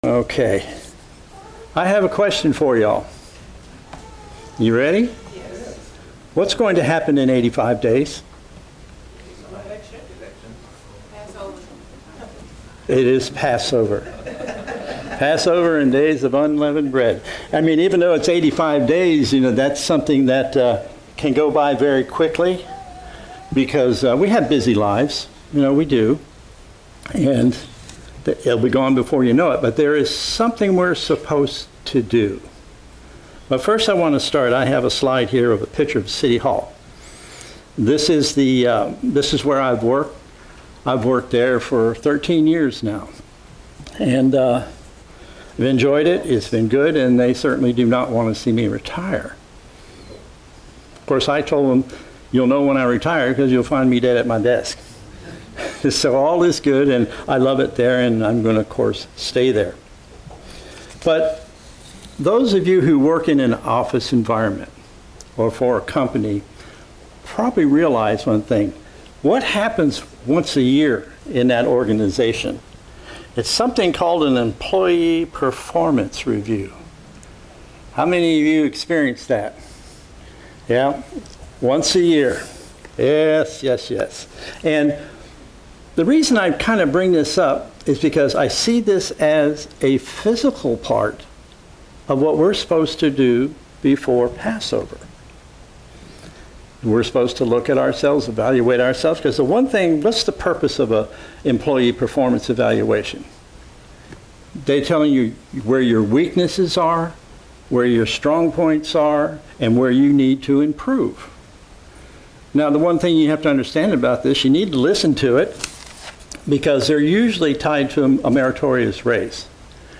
Sermons
Given in Ft. Lauderdale, FL